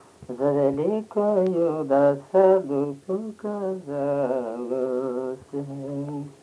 Произнесение частицы –ся в возвратных формах глаголов как –се